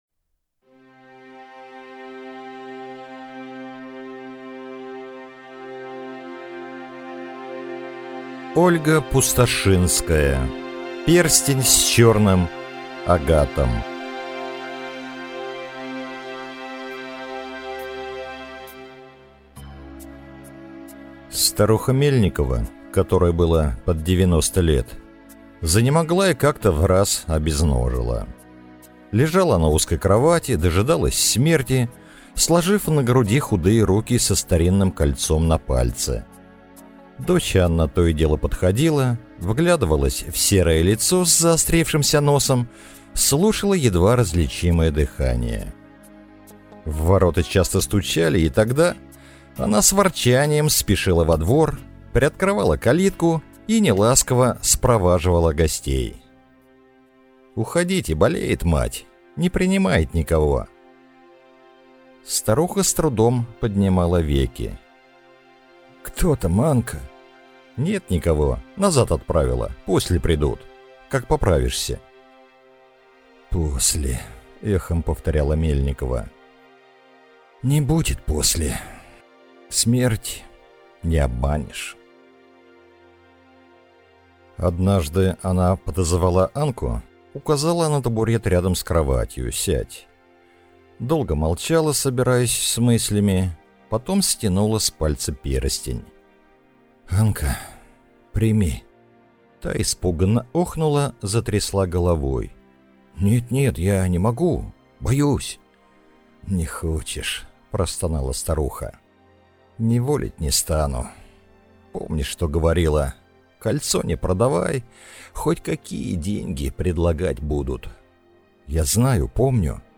Аудиокнига Перстень с чёрным агатом | Библиотека аудиокниг